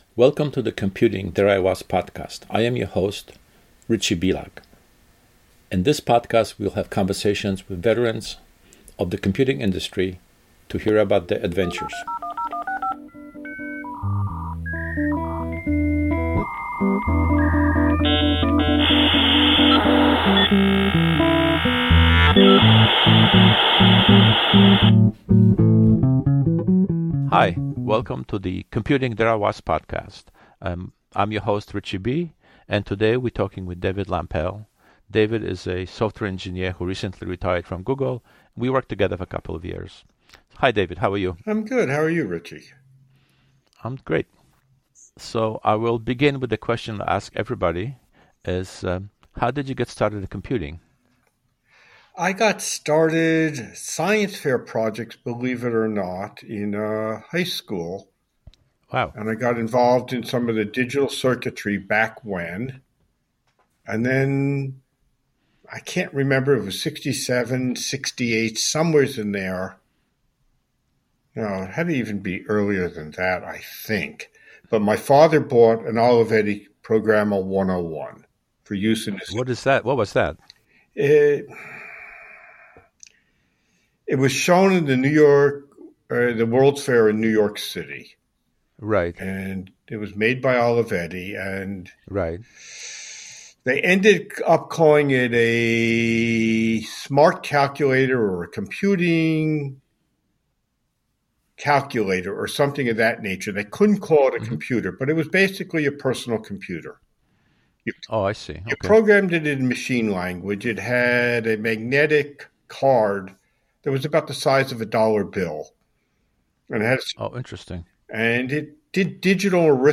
1 Episode 5: "Computing...There I was" - Conversation